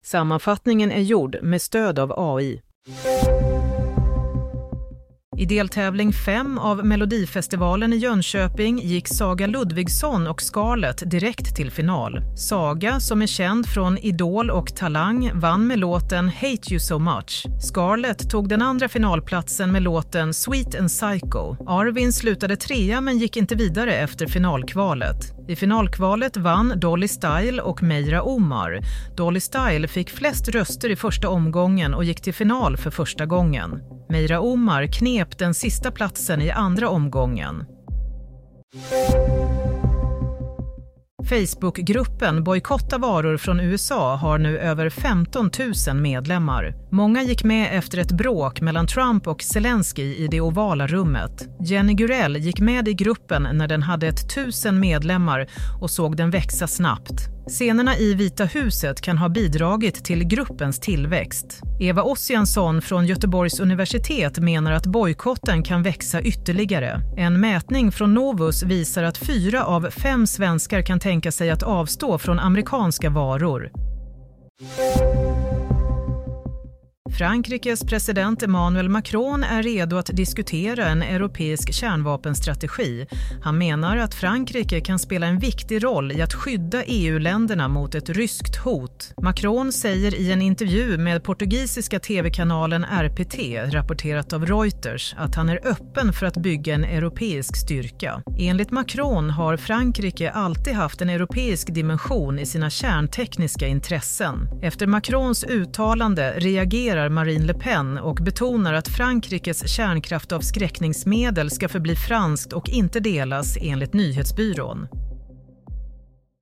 Nyhetssammanfattning - 1 mars 22.30
Sammanfattningen av följande nyheter är gjord med stöd av AI.